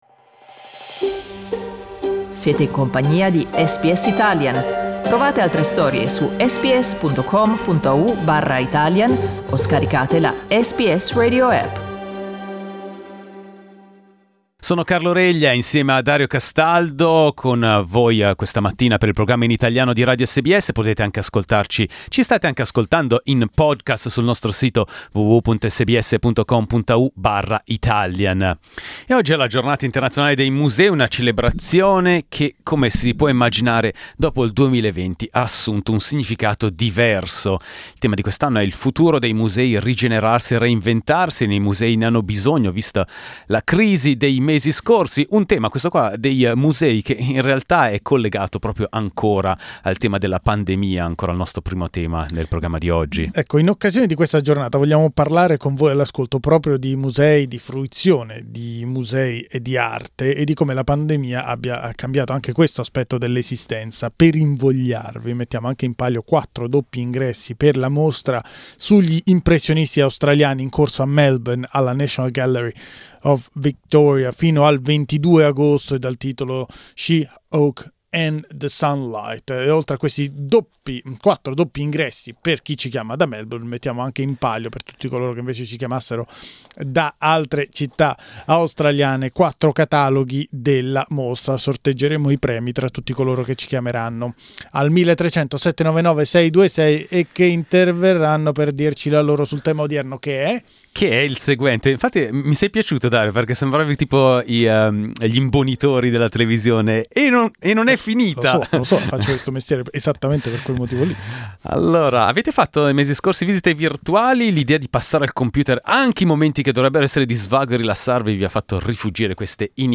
Ecco cosa ne pensano gli ascoltatori di SBS Italian.